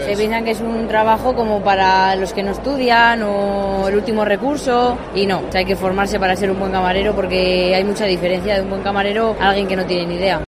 Estudiantes de hostelería defienden el valor de la formación